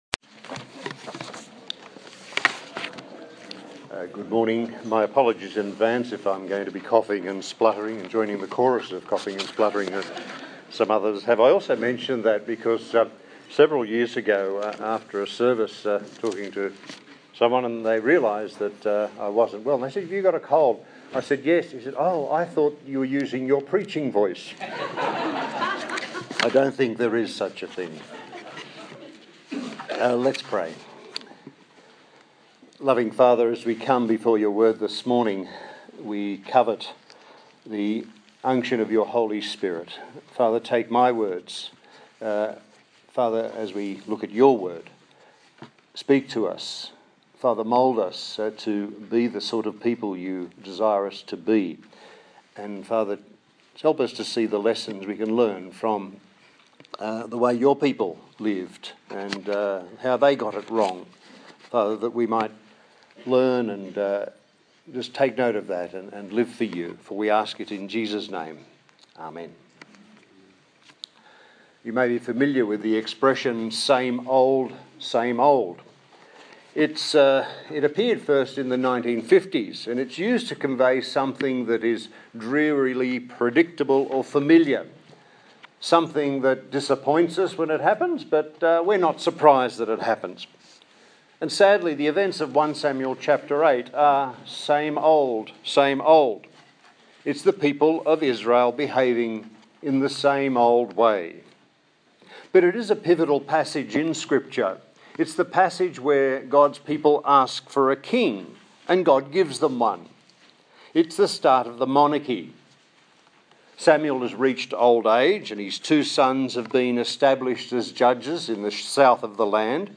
1 Samuel Passage: 1 Samuel 8 Service Type: Sunday Morning